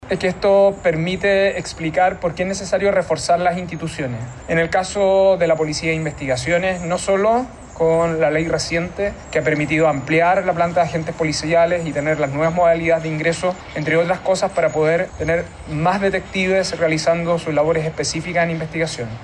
El ministro de Seguridad, Luis Cordero, felicitó el trabajo realizado por la PDI en coordinación con el Ministerio Público y recalcó que los resultados de operativos de este tipo demuestran que es necesario reforzar las instituciones.
ministro-detenidos-los-piratas.mp3